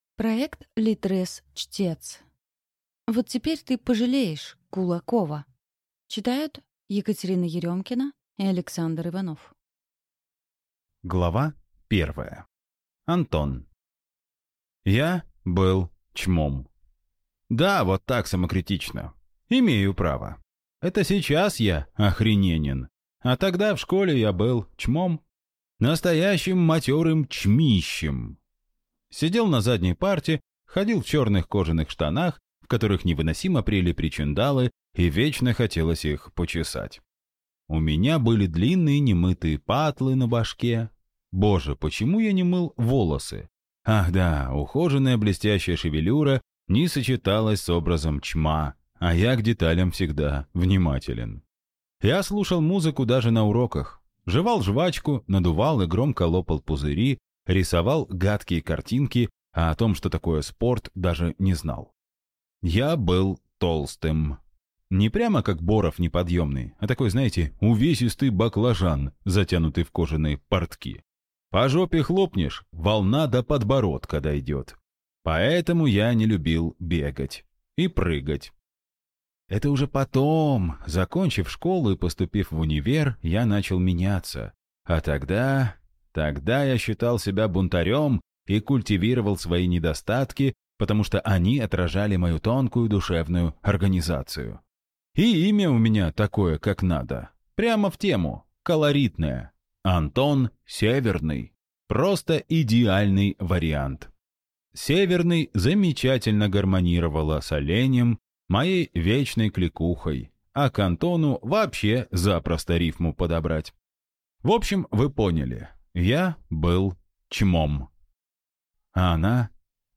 Аудиокнига Вот теперь ты пожалеешь, Кулакова!